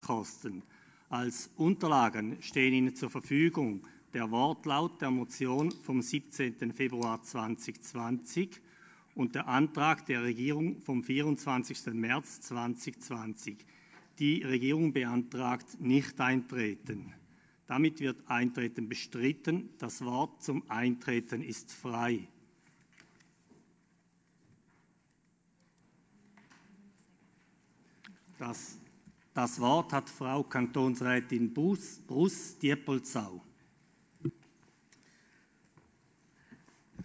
18.5.2020Wortmeldung
Session des Kantonsrates vom 18. bis 20. Mai 2020, Aufräumsession